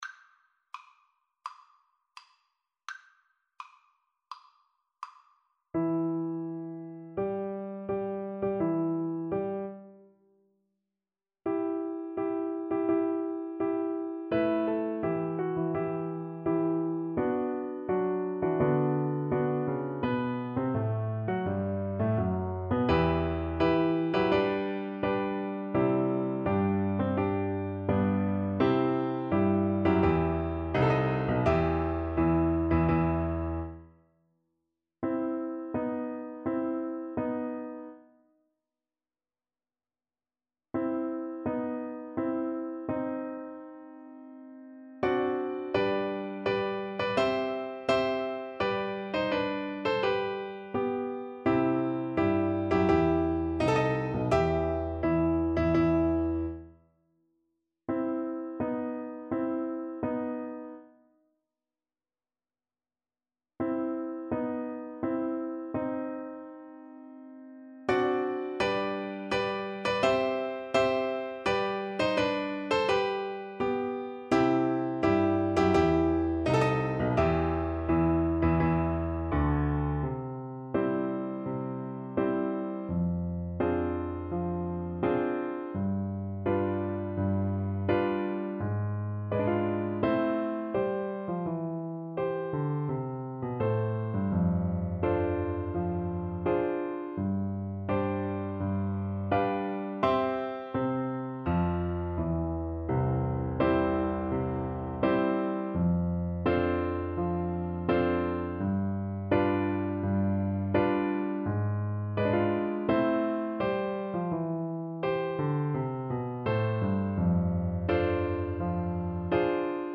4/4 (View more 4/4 Music)
Tempo di Marcia =84
Classical (View more Classical French Horn Music)